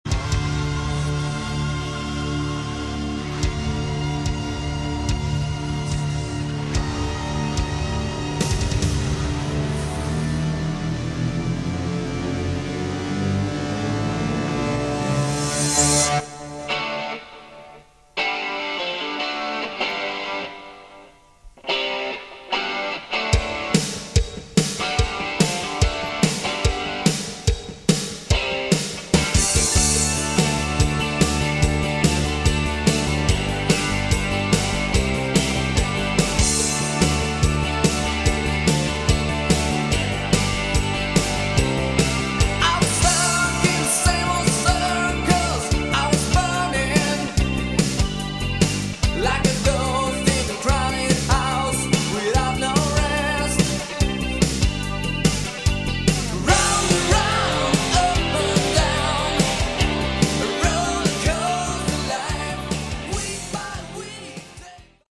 Category: Hard Rock
Vocals, Guitar, Bass, Keyboards, Drums